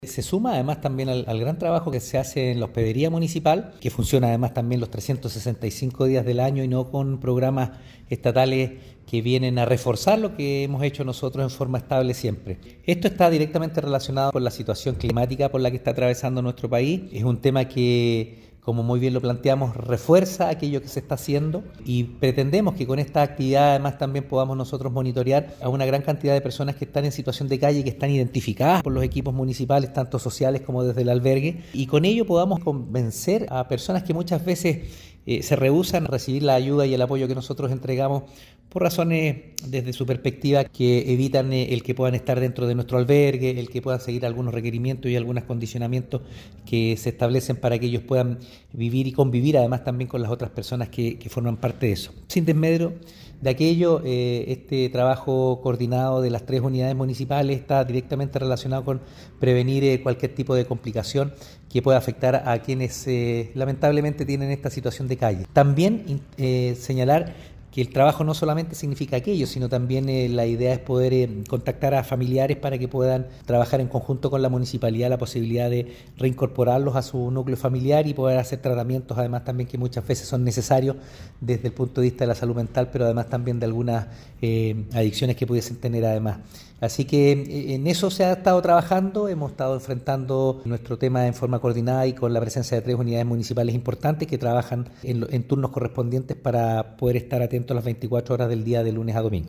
Alcalde-Oscar-Calderon-Sanchez-6.mp3